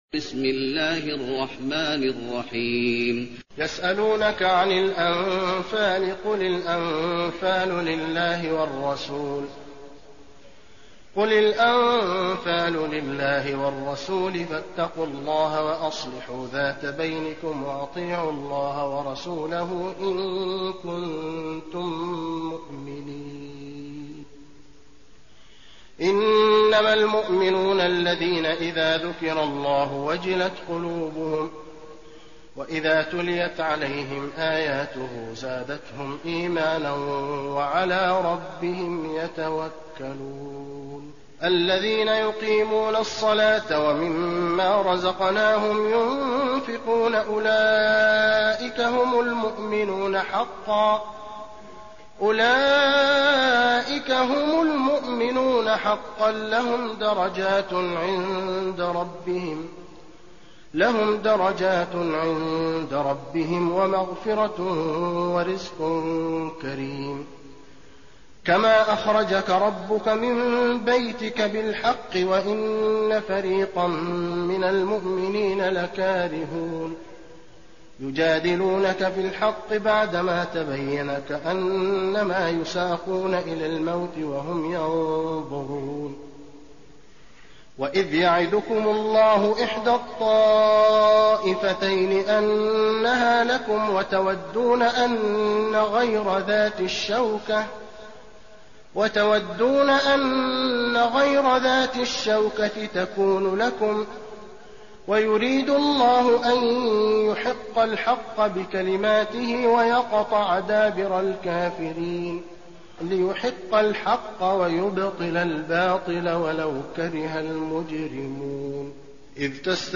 المكان: المسجد النبوي الأنفال